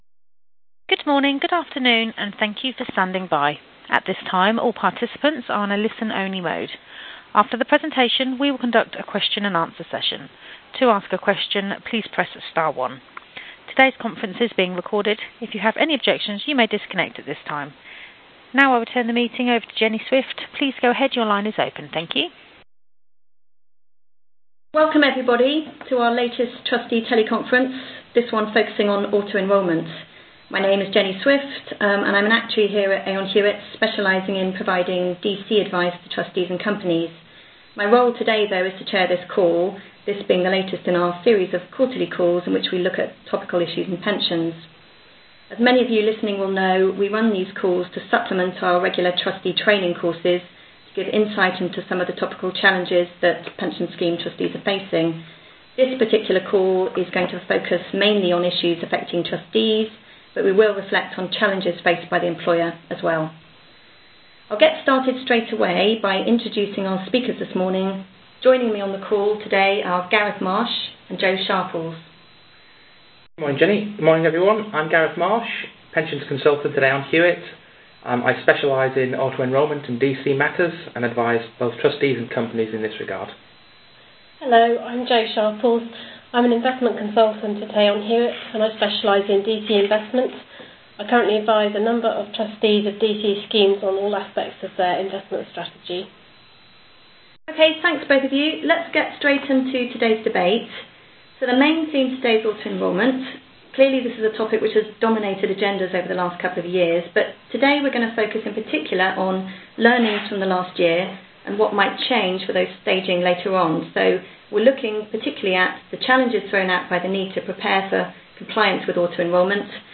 Recorded Teleconference: Topical Trustee Issues | United Kingdom
At this teleconference the panel will be discussing auto-enrolment, including: